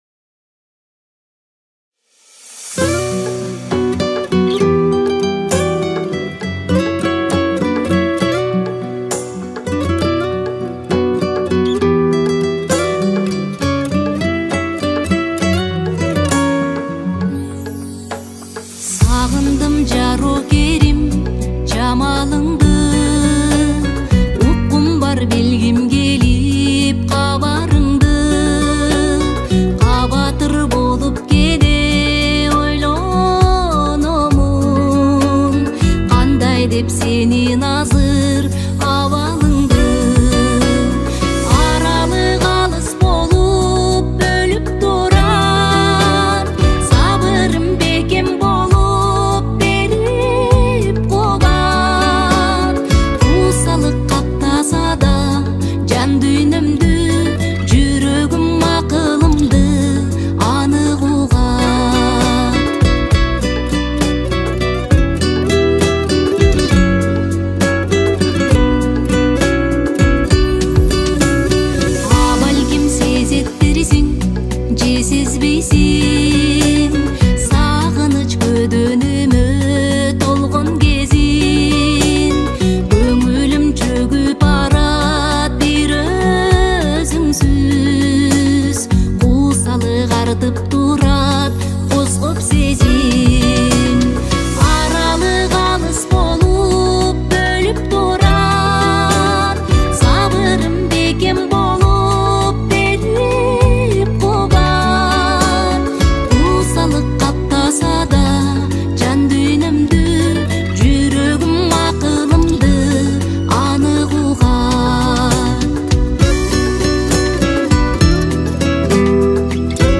• Киргизские песни